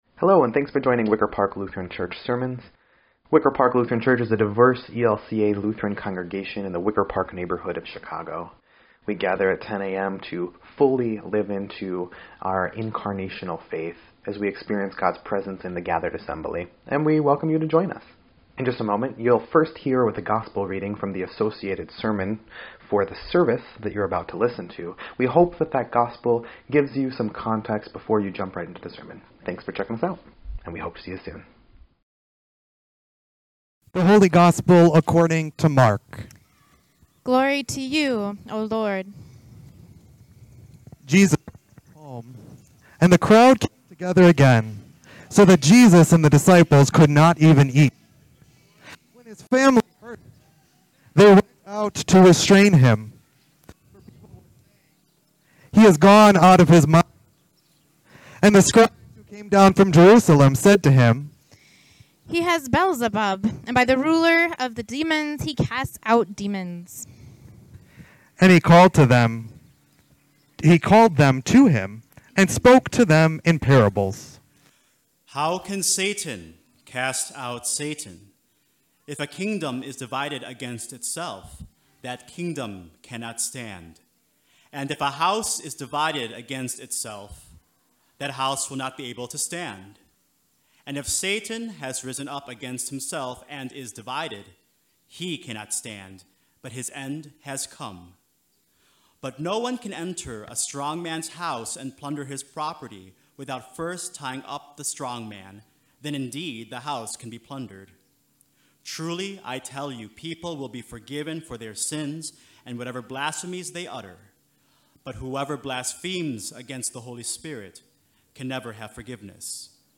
6.6.21-Sermon_EDIT.mp3